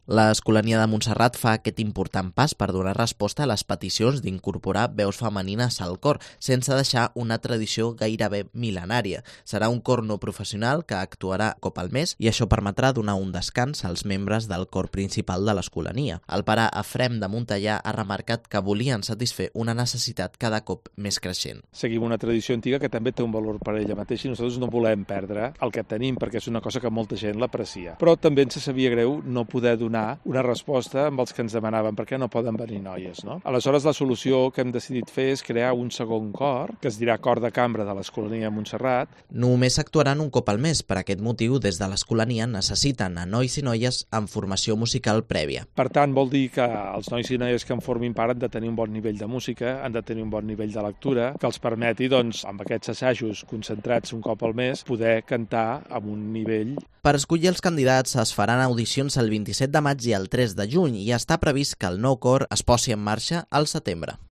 crónica sobre el coro mixto de la Escolanía de Montserrat